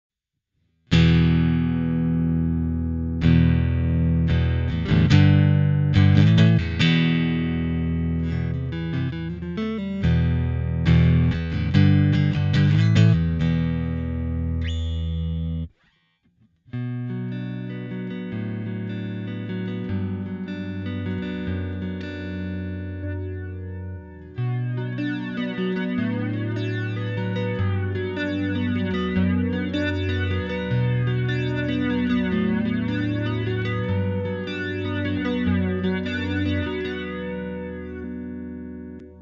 Flanging
Audio process that combines two copies of the same signal, with the second delayed slightly, to produce a swirling effect.
Sobre el audio: [0:00-0:22] Guitarra sin ningún efecto. [0:23-0:39] Guitarra con efecto flanging.
Flanging.mp3